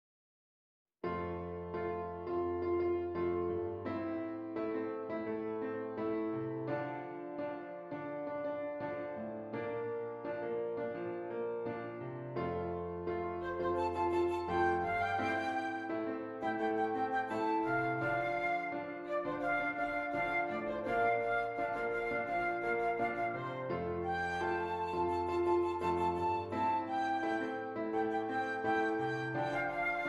Flute Solo with Piano Accompaniment
Does Not Contain Lyrics
F Major
Andante